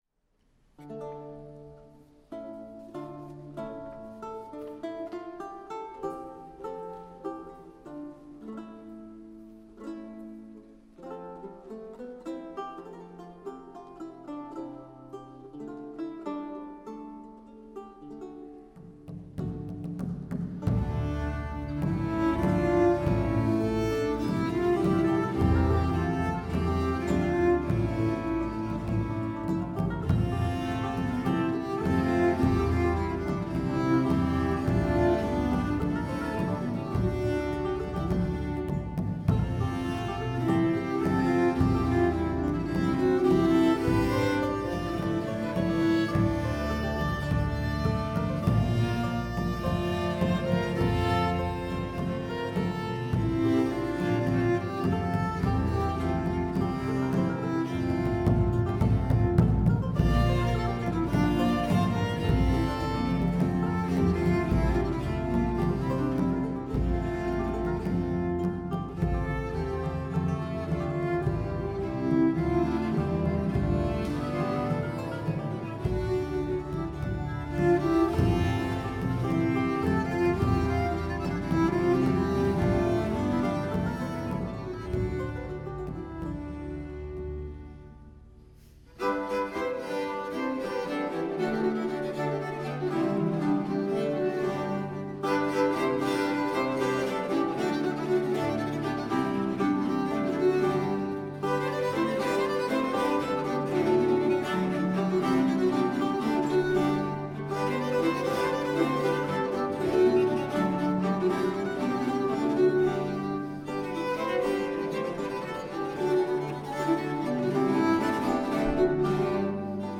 Musique : Pavane et Gaillarde Votre navigateur n'est pas compatible Le fichier de musique : pavane&gaillarde.ogg La lumière d'un matin.
pavane&gaillarde.ogg